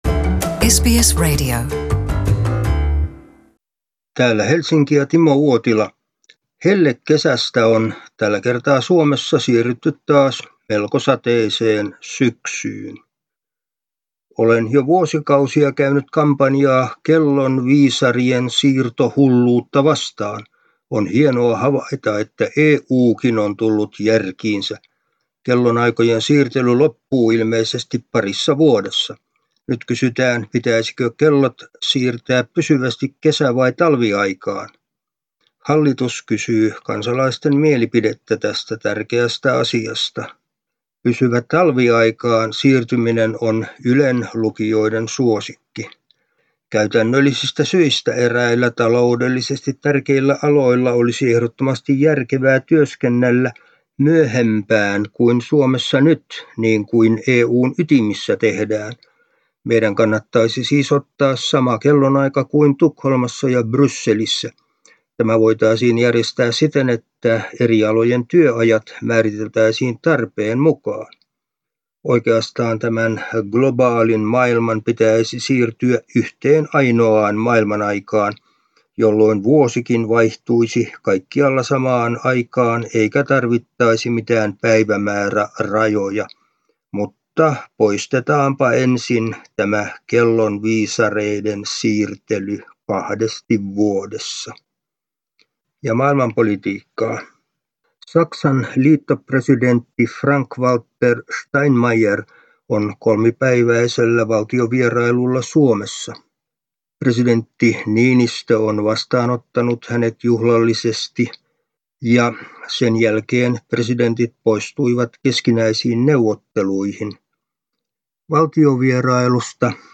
ajankohtaisraportti Suomesta